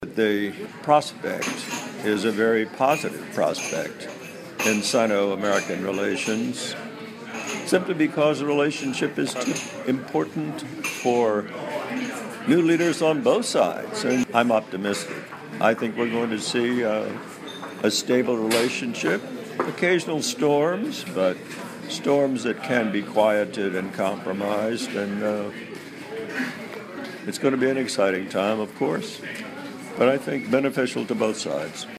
VOA专访里根国安顾问麦克法兰(1)